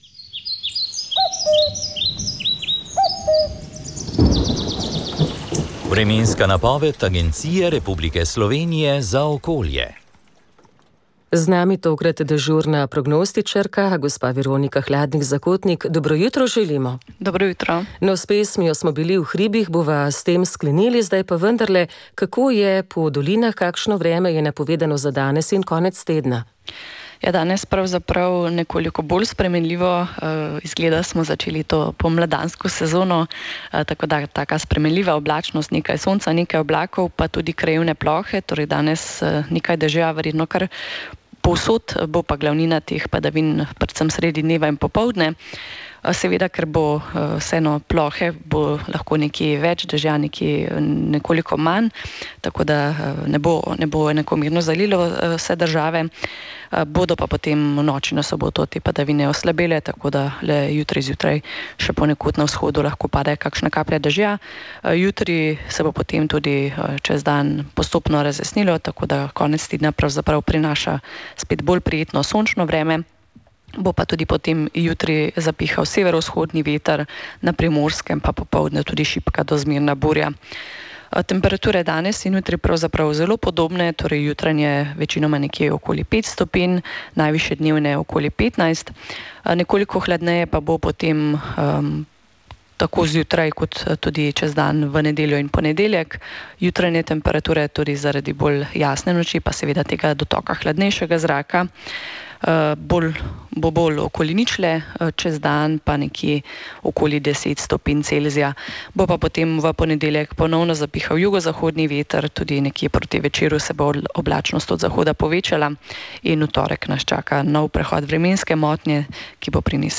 Vremenska napoved 10. marec 2023